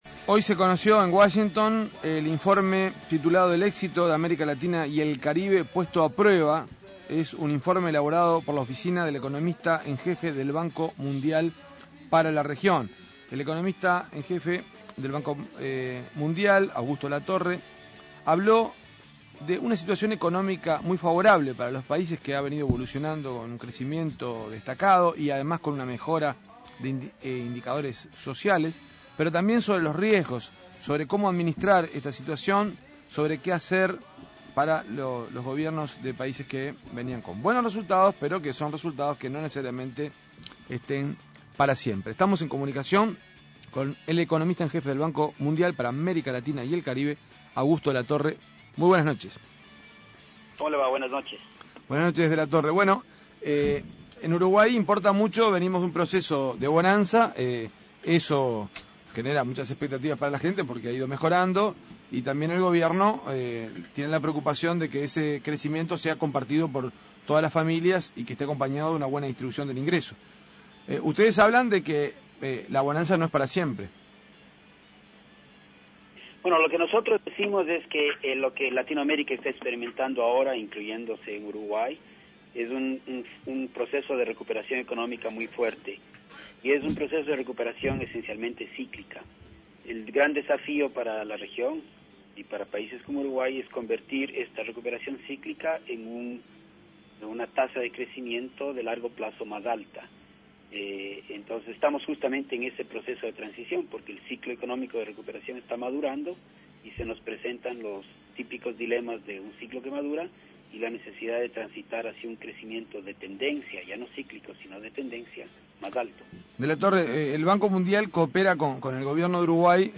En comunicación con El Espectador desde Washington